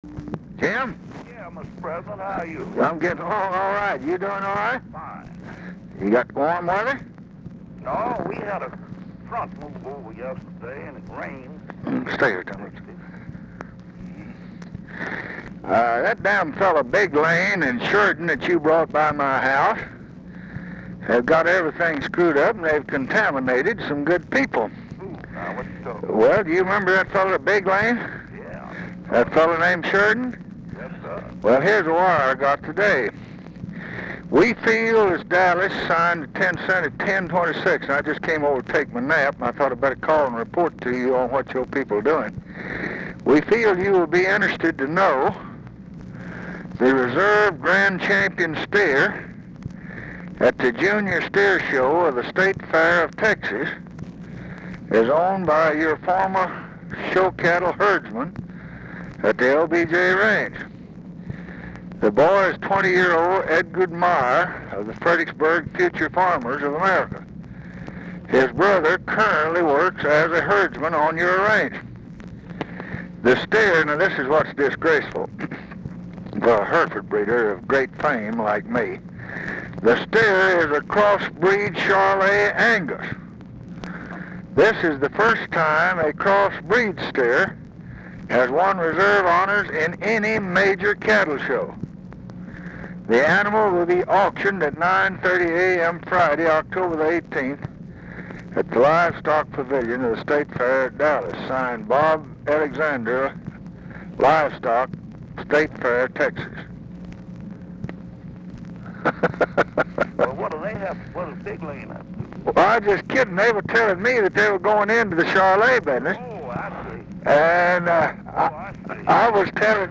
Telephone conversation # 13563, sound recording, LBJ and JAMES EASTLAND, 10/18/1968, 3:54PM | Discover LBJ
Format Dictation belt
Location Of Speaker 1 Mansion, White House, Washington, DC
Specific Item Type Telephone conversation